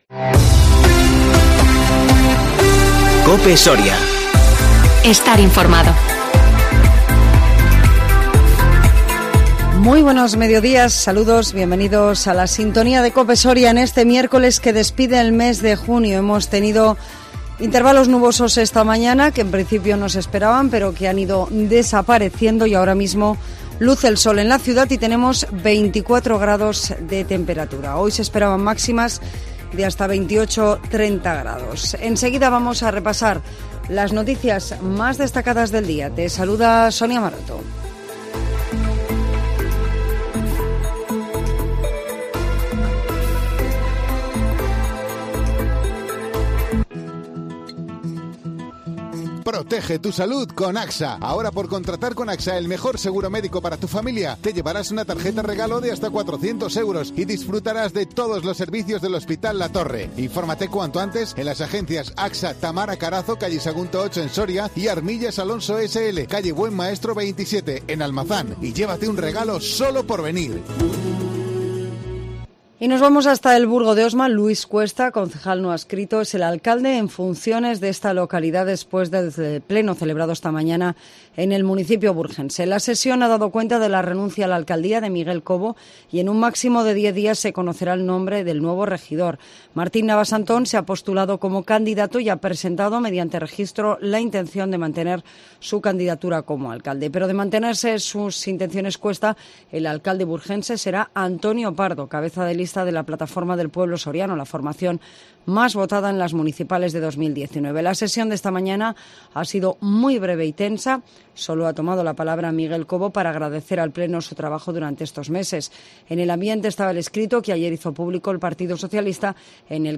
INFORMATIVO MEDIODÍA 30 JUNIO 2021